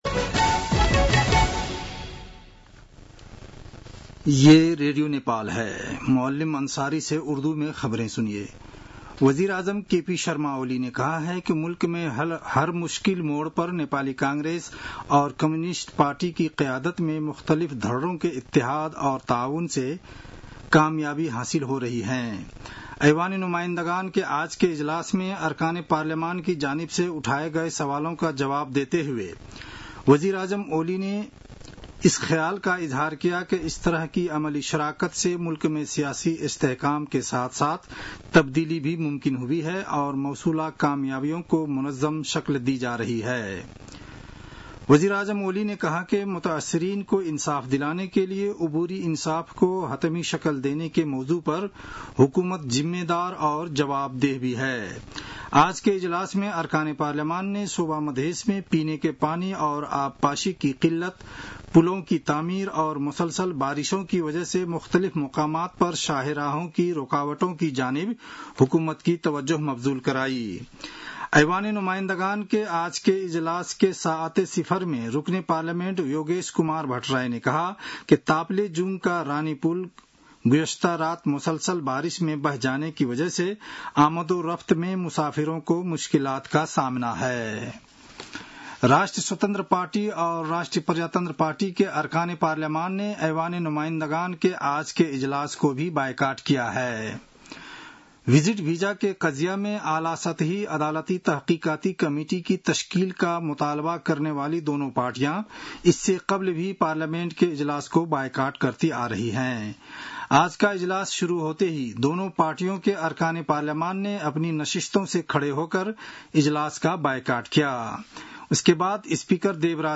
उर्दु भाषामा समाचार : ७ साउन , २०८२
Urdu-news-4-07.mp3